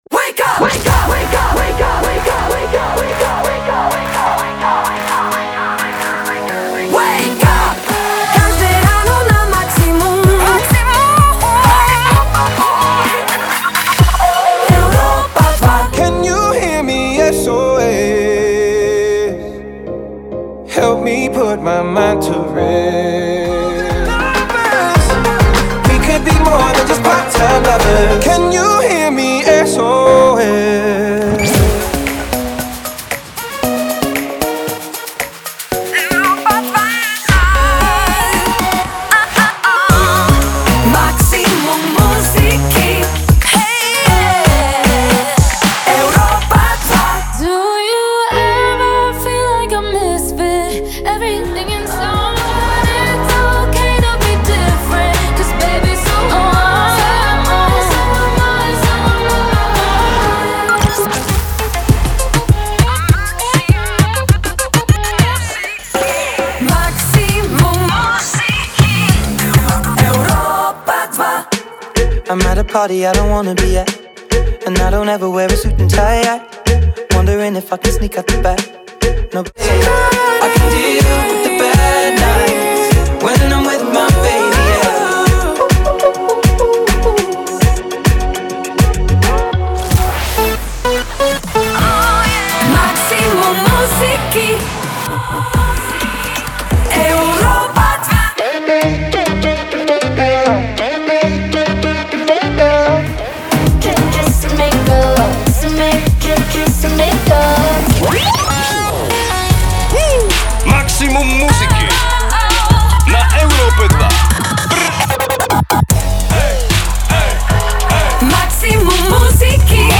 jingle package